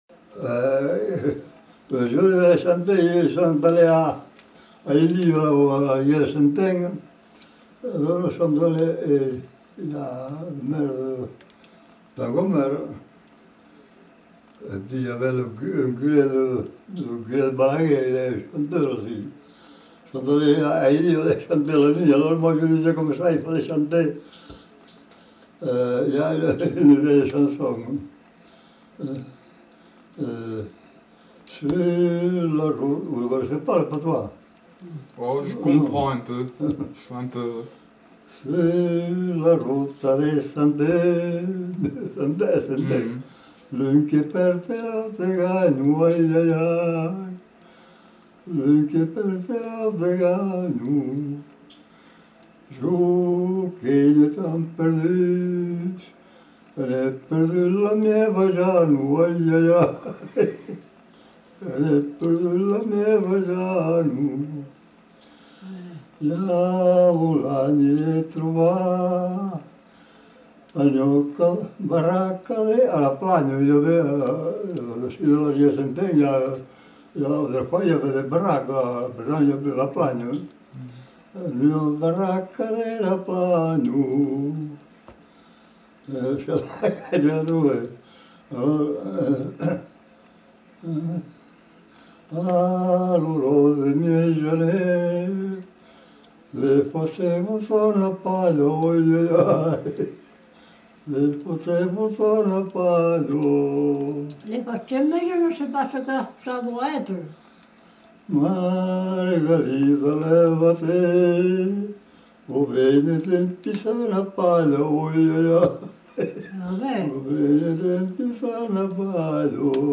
Aire culturelle : Couserans
Genre : chant
Effectif : 1
Type de voix : voix d'homme
Production du son : chanté